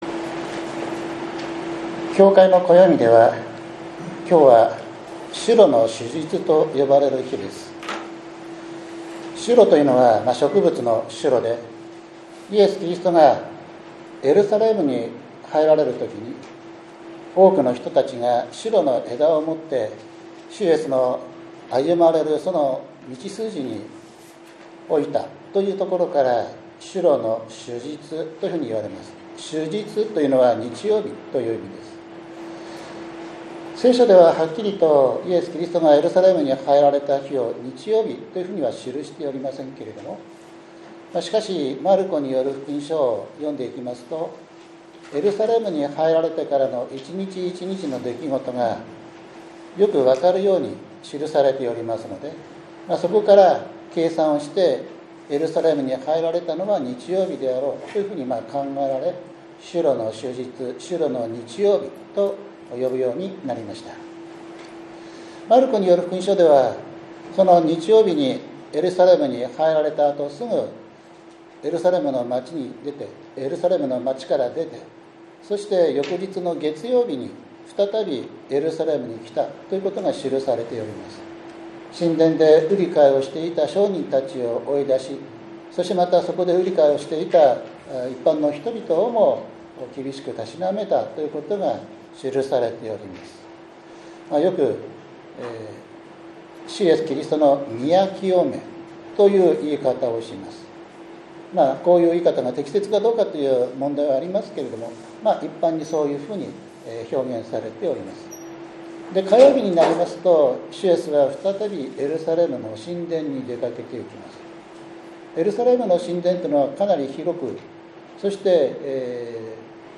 ４月１３日（日）主日礼拝 詩編２２編２節 マルコによる福音書１５章３３節～４１節